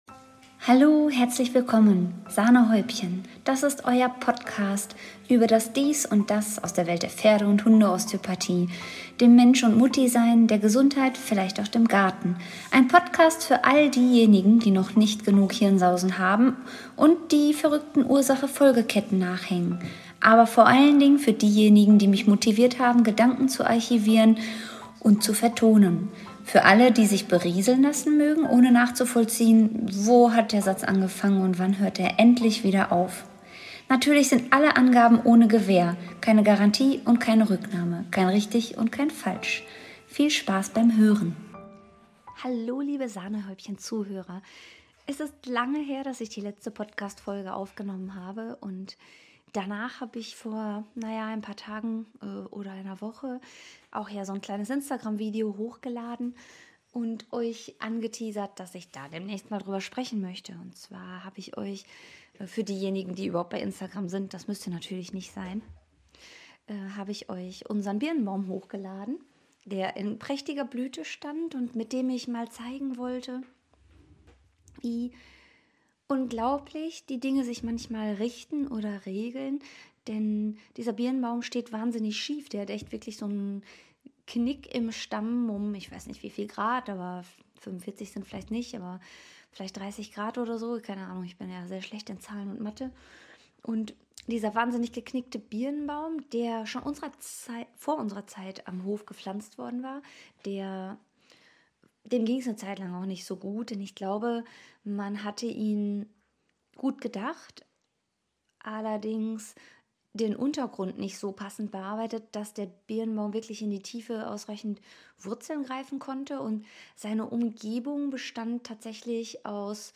Durch die Gefahr die seit dem GeschichtsLK in der Oberstufe nicht auszumerzenden verschachtelten Sätze und den erhöhten Redefluss besteht hier wieder erhöhte Gefahr von Hirnsausen….